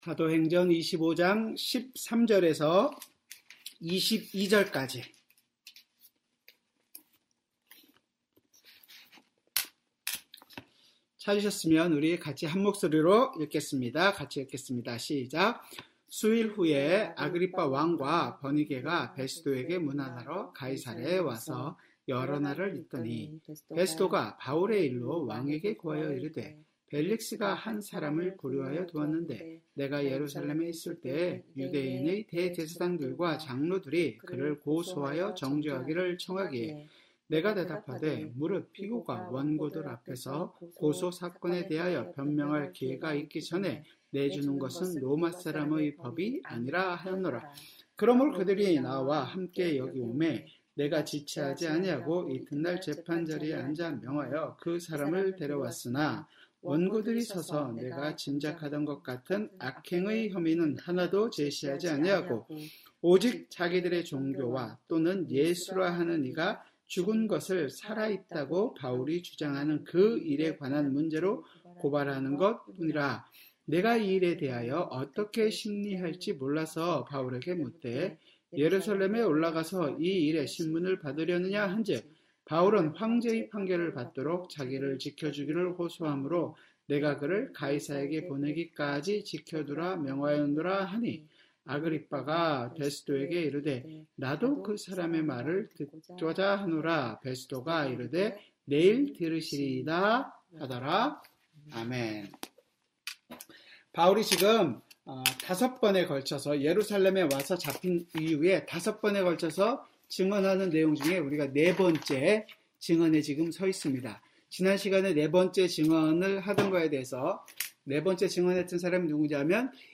수요성경공부